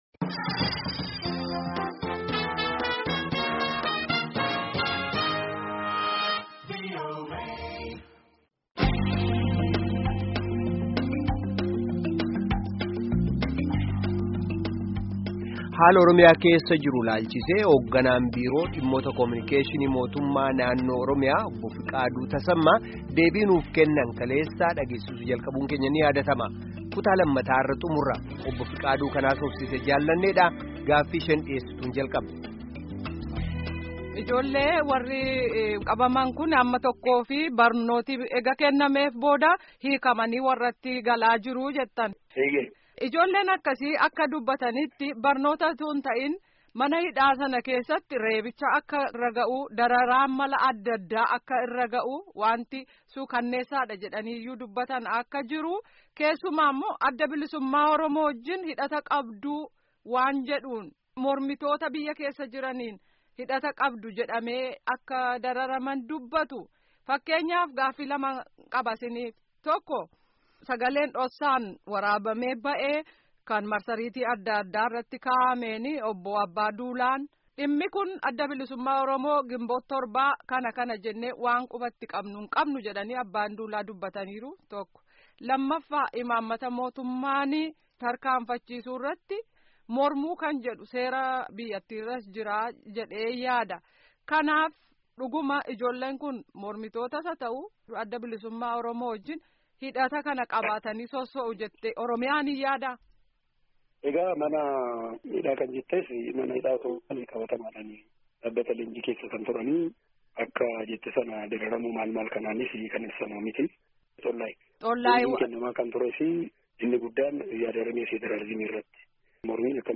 Gaafii fi deebii